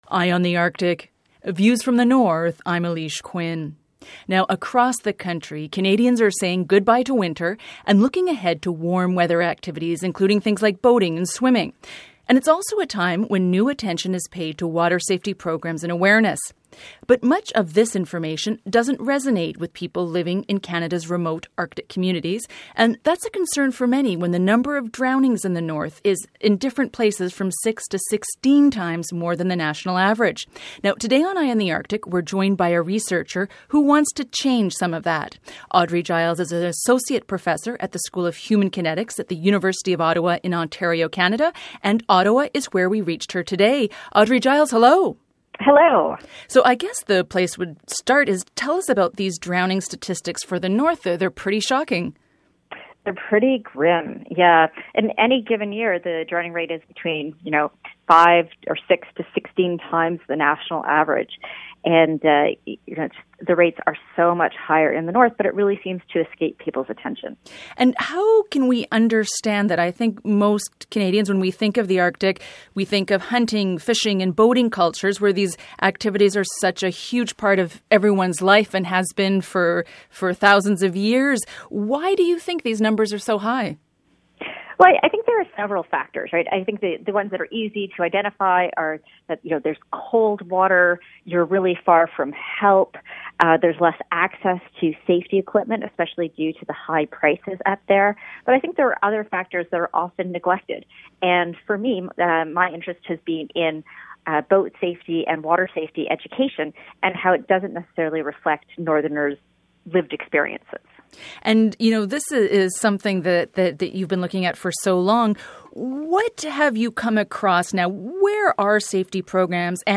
in a telephone interview from Ottawa.